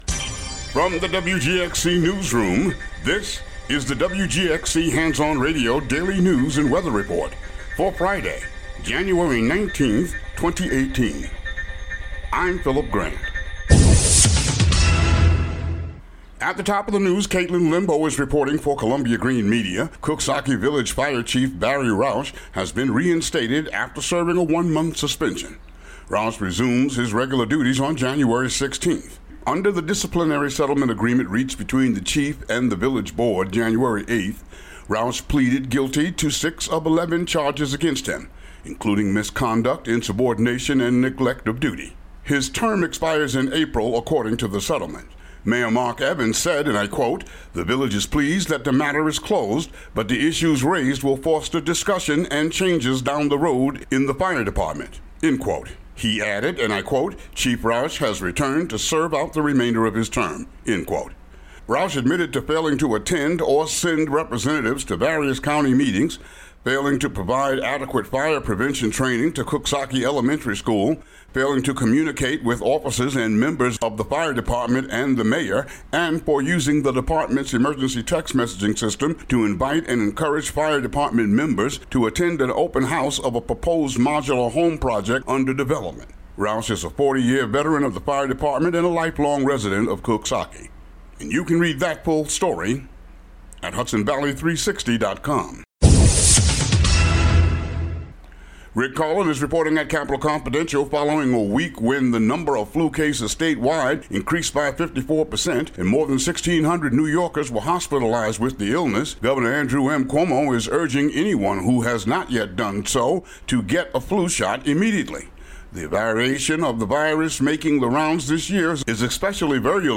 Daily local news for Fri., Jan. 19.